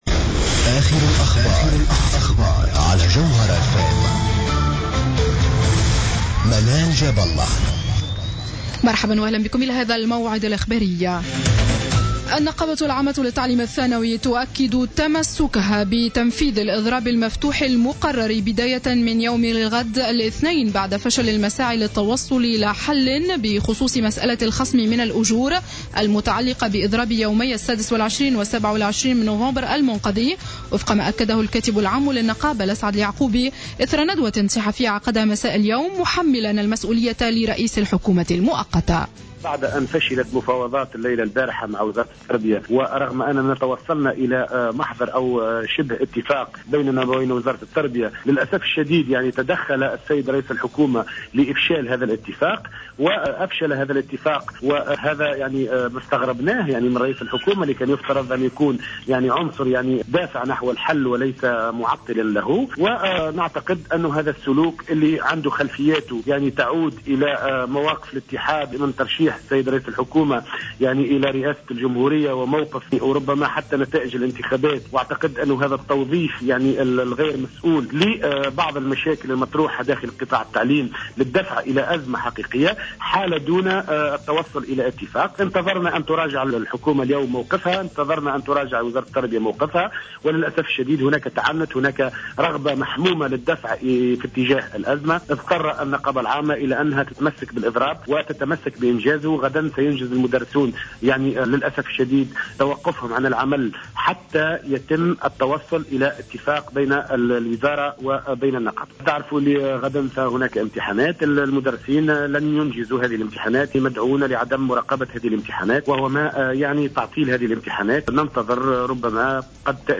نشرة أخبار السابعة مساء ليوم الأحد 07-12-14